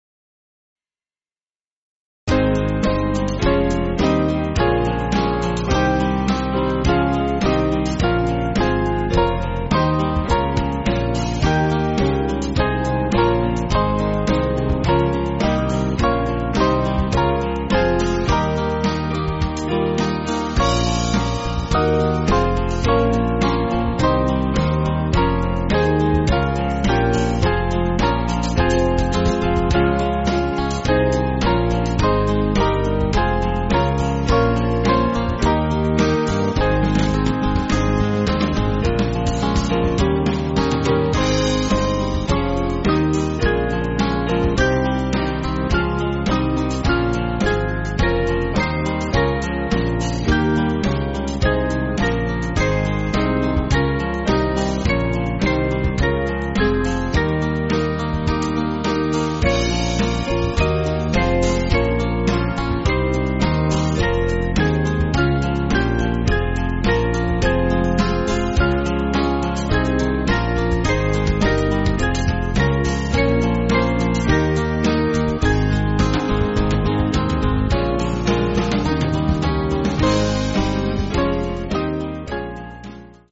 Band MP3
Small Band